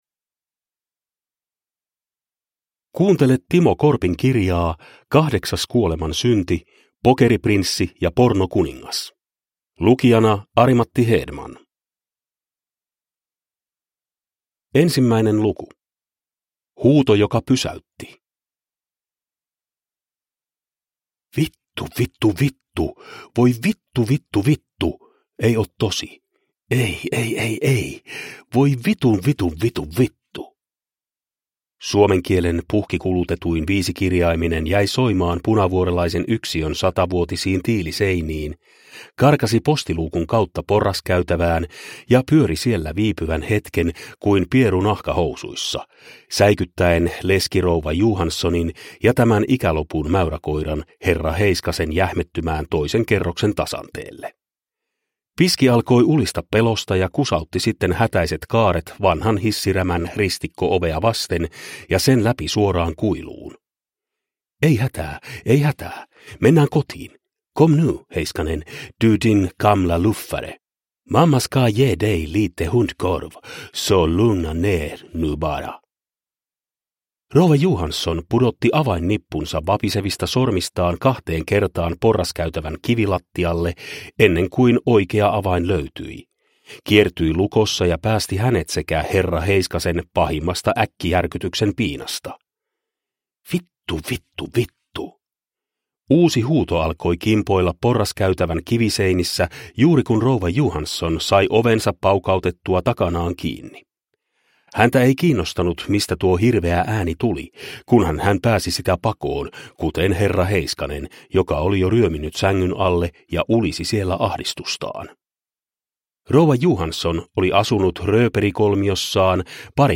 Kahdeksas kuolemansynti (ljudbok) av Timo Korppi